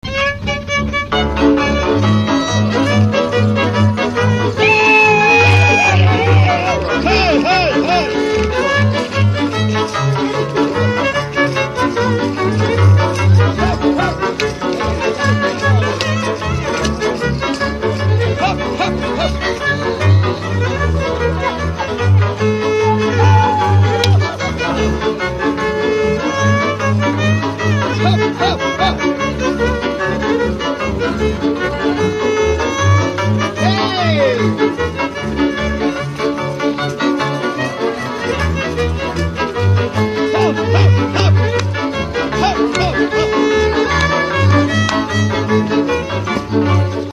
Dallampélda: Hangszeres felvétel
Dunántúl - Sopron vm. - Szany
Előadó: vonós zenekar, vonós zenekar
Műfaj: Dus
Stílus: 2. Ereszkedő dúr dallamok